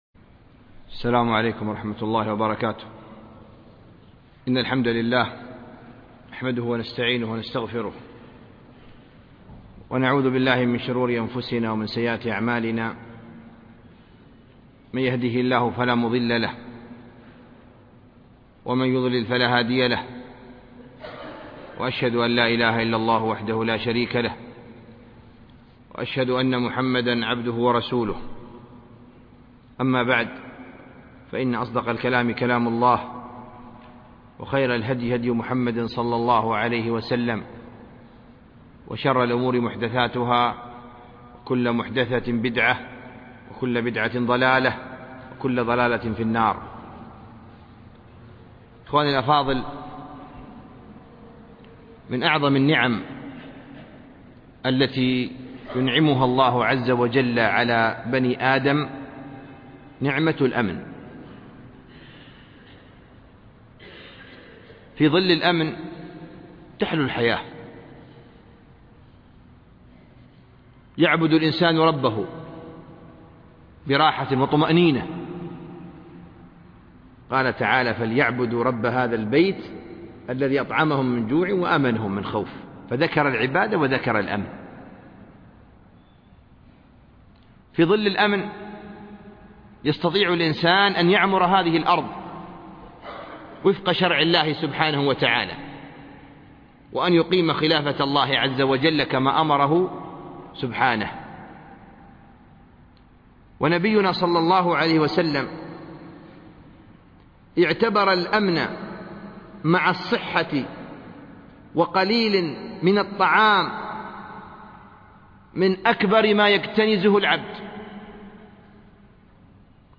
ضرورة شرعية الألبوم: شبكة بينونة للعلوم الشرعية المدة: 53:18 دقائق (12.22 م.بايت) التنسيق: MP3 Mono 11kHz 32Kbps (CBR)